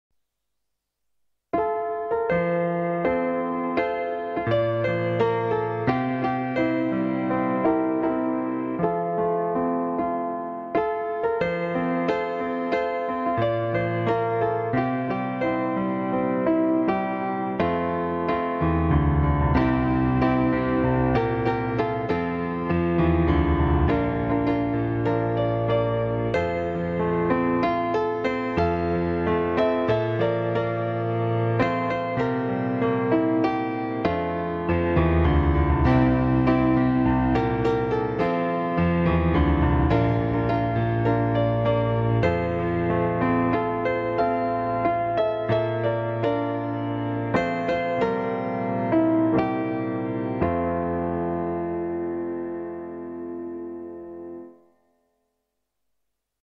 Гимн Польши на пианино